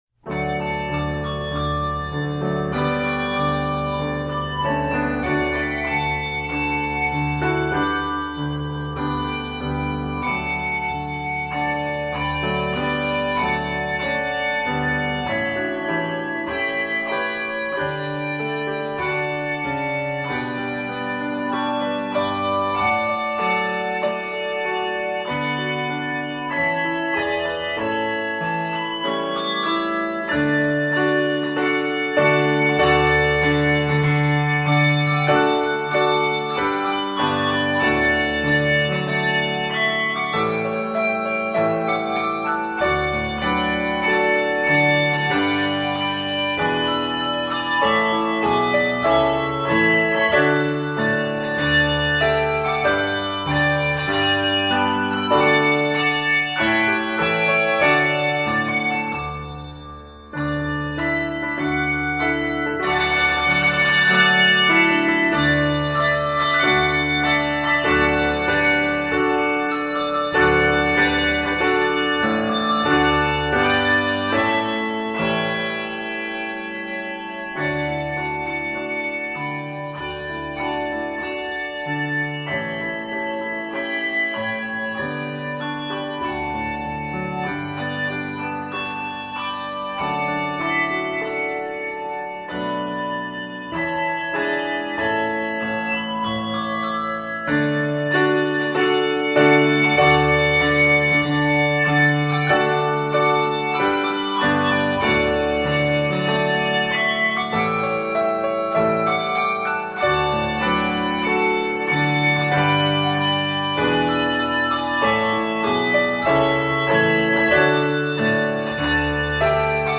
12-bell arrangement